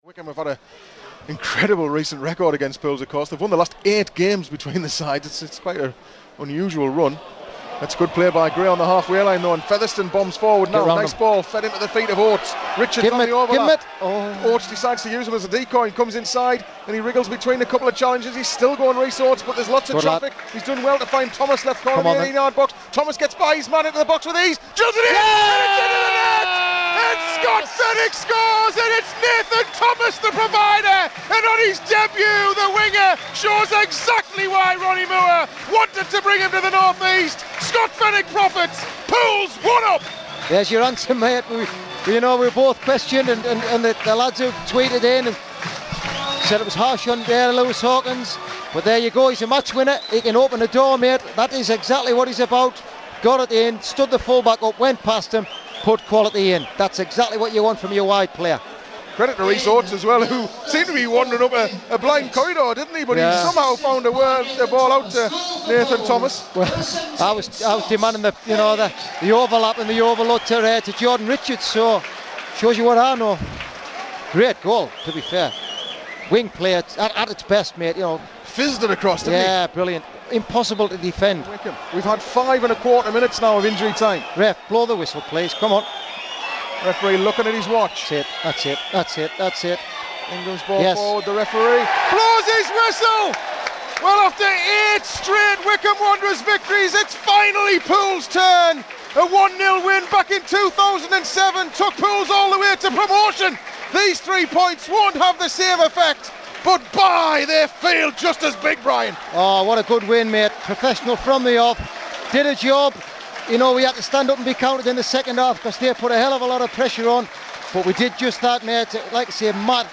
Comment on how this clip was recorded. Listen back to how the key moments from Saturday's win over Wycombe sounded as they happened live on Pools PlayerHD.